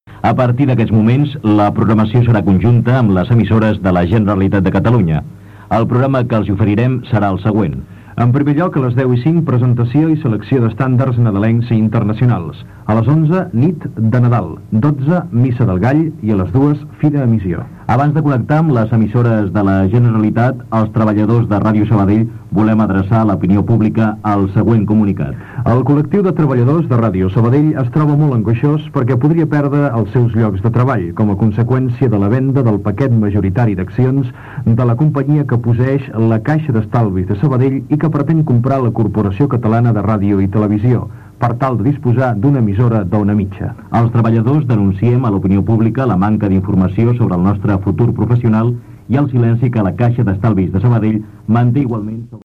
Avanç de la programació en connexió amb Catalunya Ràdio.